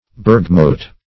bergmote - definition of bergmote - synonyms, pronunciation, spelling from Free Dictionary Search Result for " bergmote" : The Collaborative International Dictionary of English v.0.48: Bergmote \Berg"mote\, n. See Barmote .